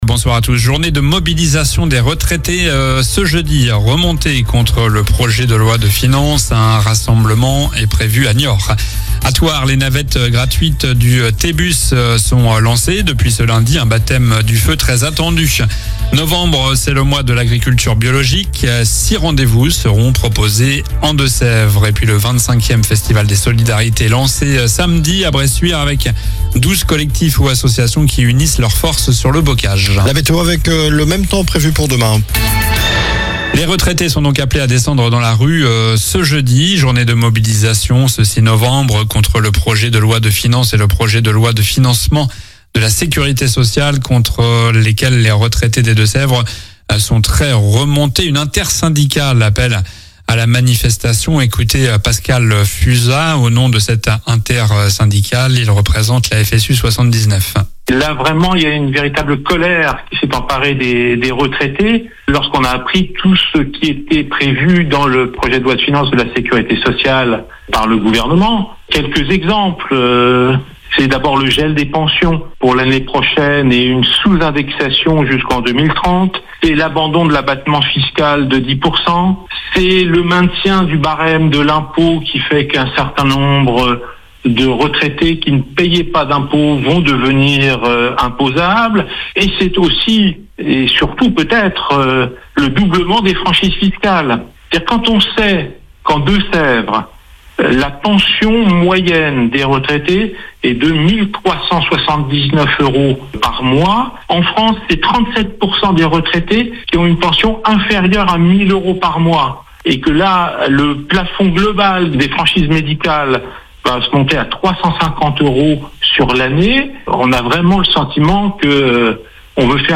Journal du lundi 3 novembre (soir)